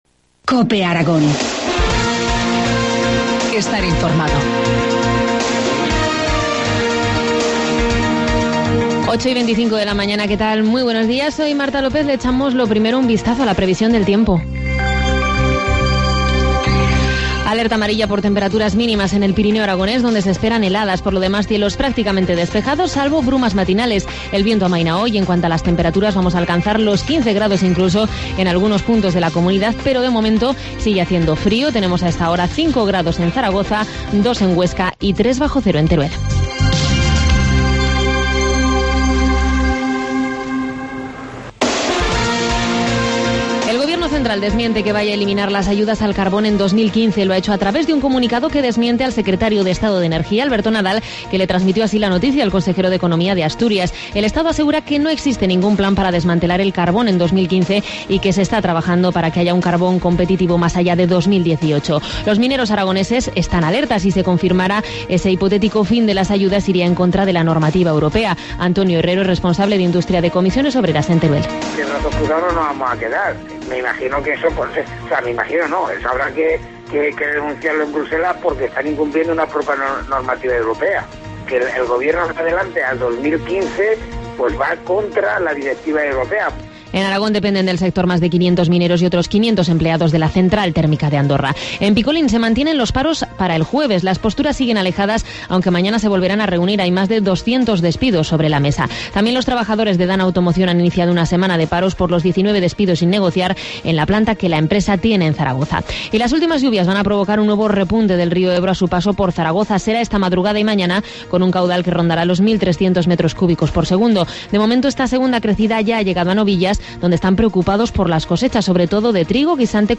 Informativo matinal, martes 29 de enero, 8.25 horas